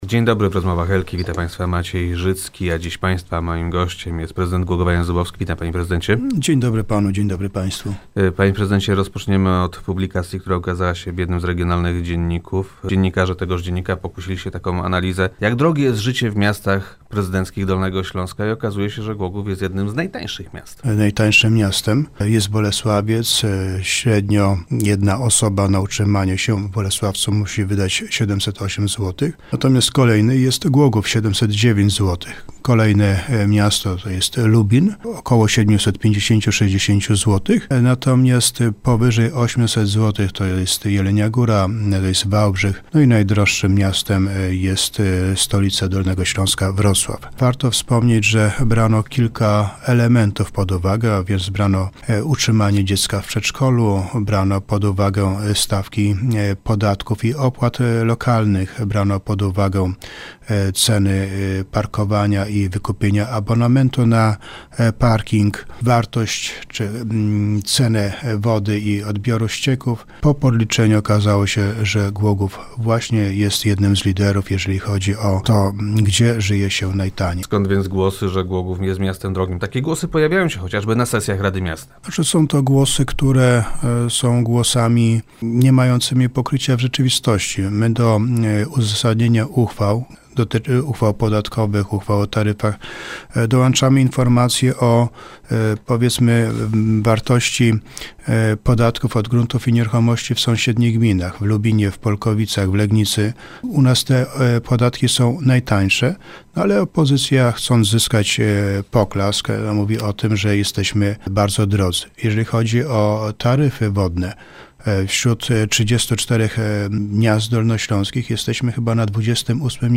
Tak przynajmniej twierdzi prezydent Jan Zubowski, który był gościem Rozmów Elki.